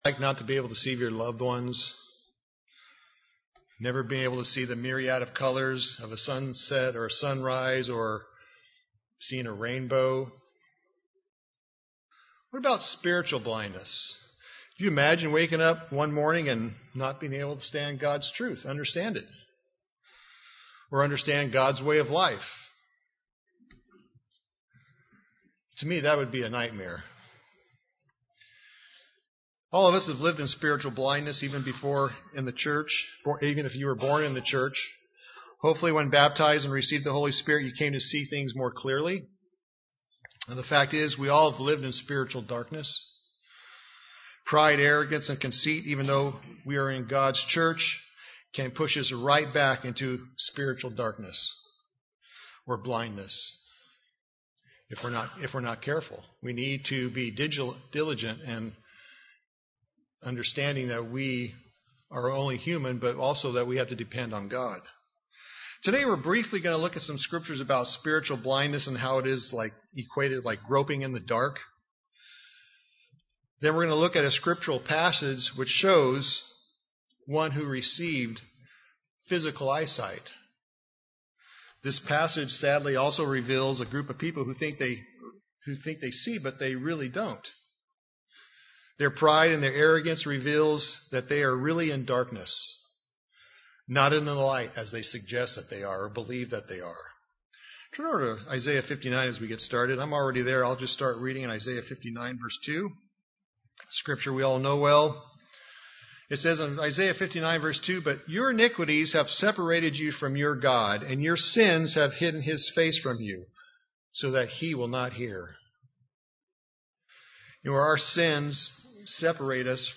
Given in Burlington, WA